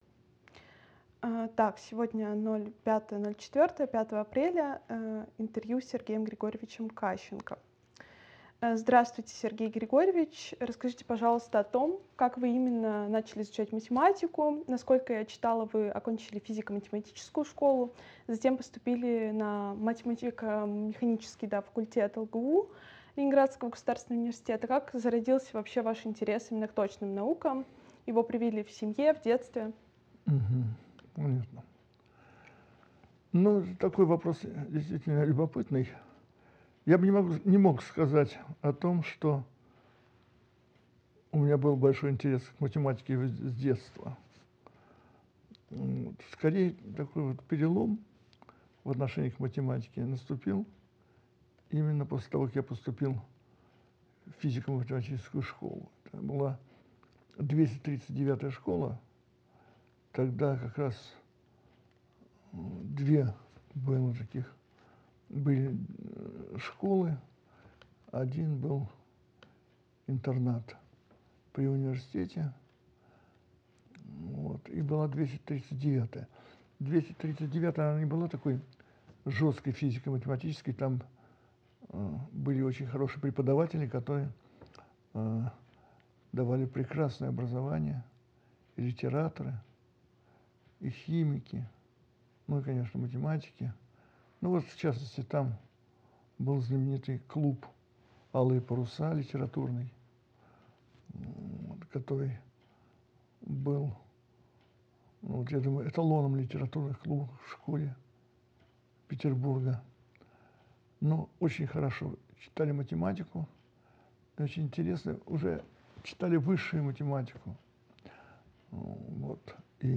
Устные воспоминания универсантов ЛГУ 1960–1980-х гг. в виртуальном пространстве